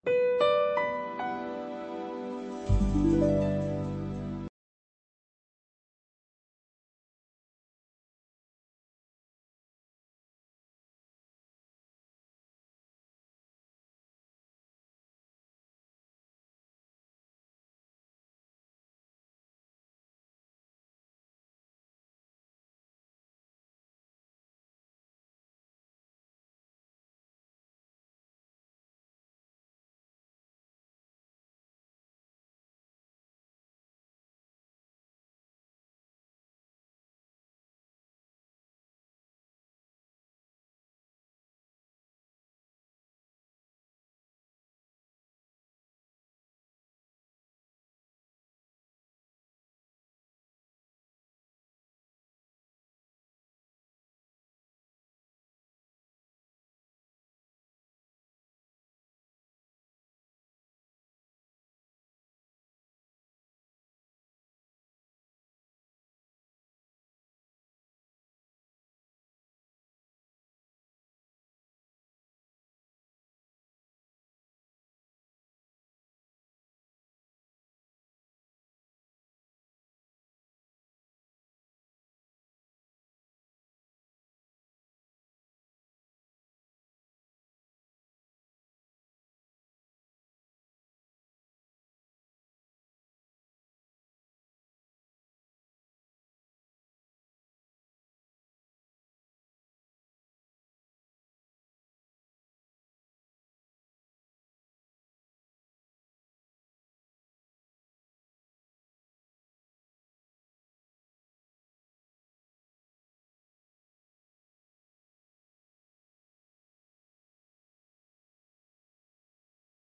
Paseo, sin narración, por las ayudas contextuales en la configuración de un glosario en la plataforma Ágora